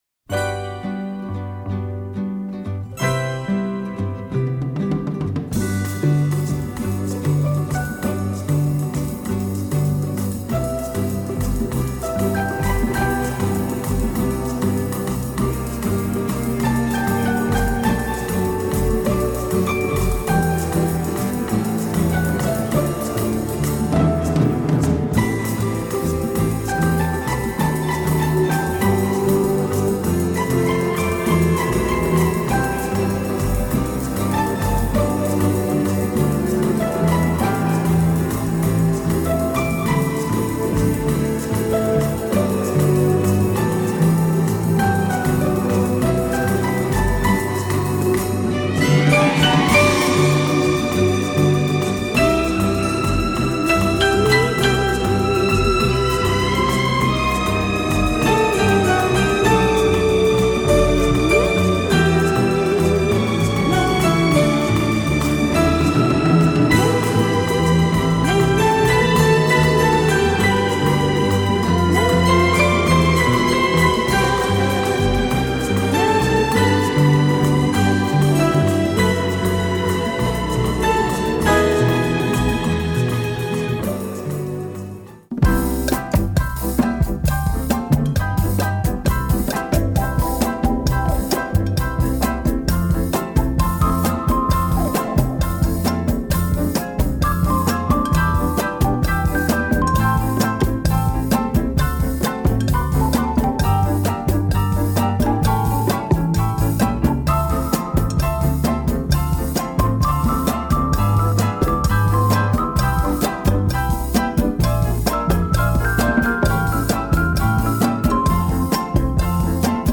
Beautiful Italian bossa jazz